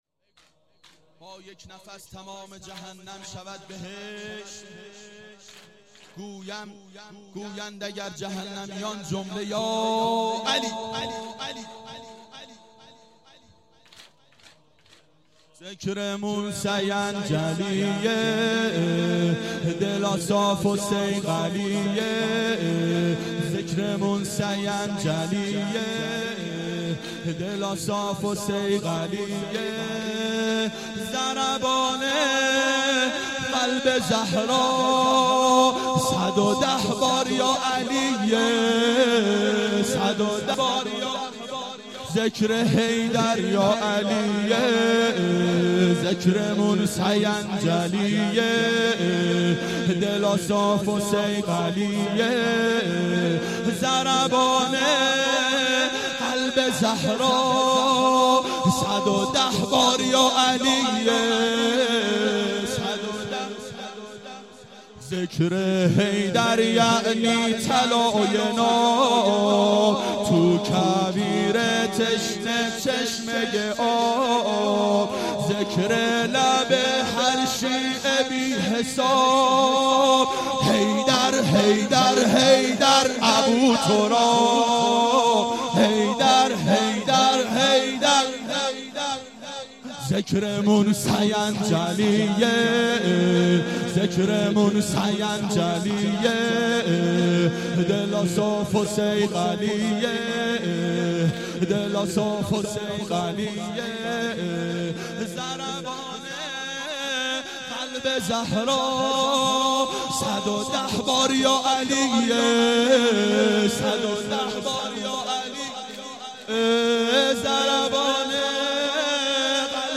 • دهه اول صفر سال 1391 هیئت شیفتگان حضرت رقیه سلام الله علیها (شام غریبان)